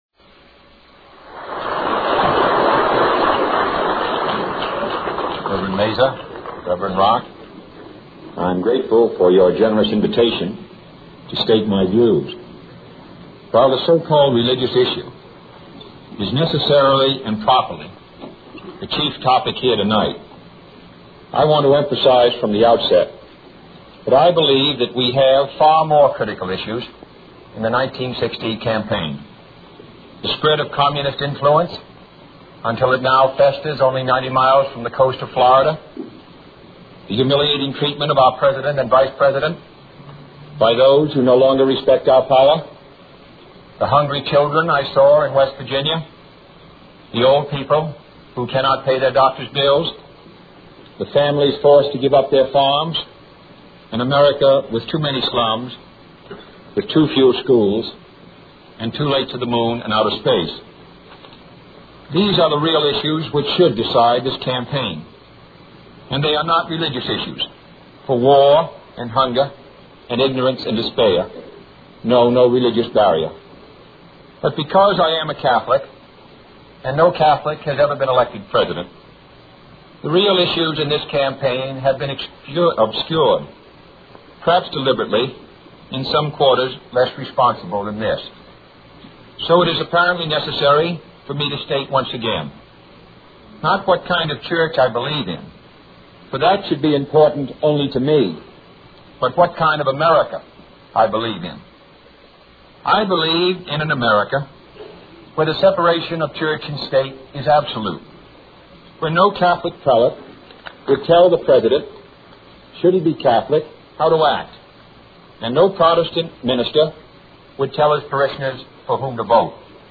John F. Kennedy: Address to the Greater Houston Ministerial Association
delivered 12 September 1960 at the Rice Hotel in Houston, TX